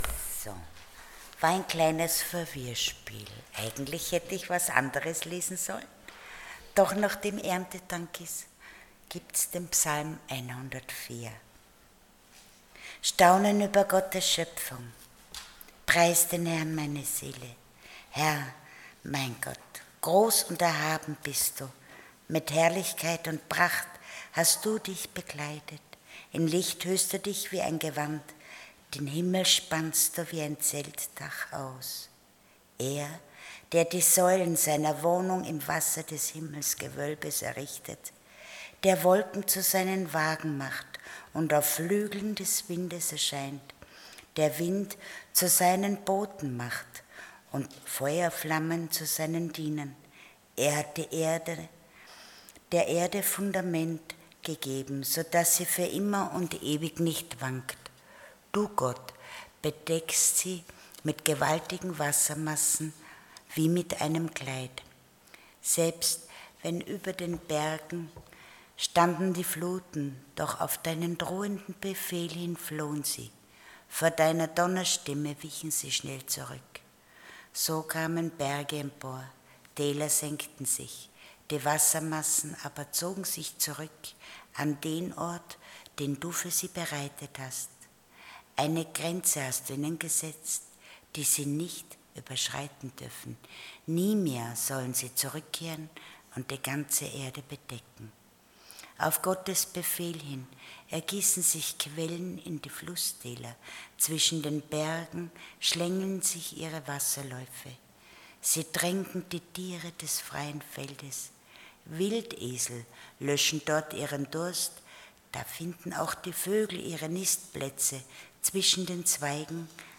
Passage: Psalm 104:1-35 Dienstart: Sonntag Morgen